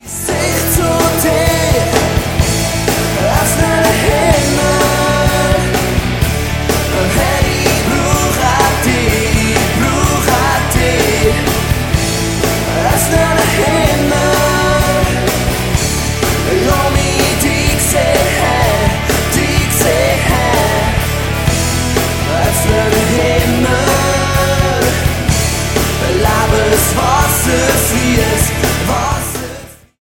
Diese Worship-CD wurde am Crea Meeting live aufgenommen.